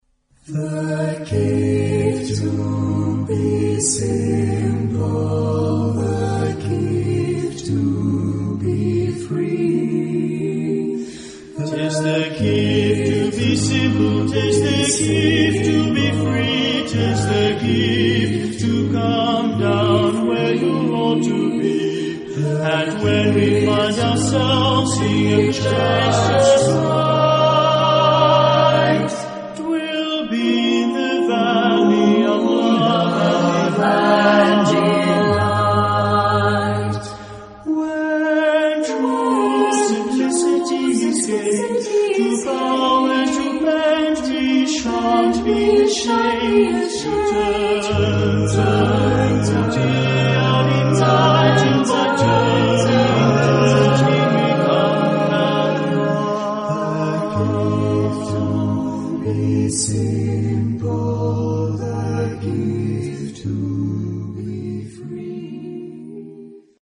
SSATBB (6 voix mixtes) ; Partition complète.
Chanson.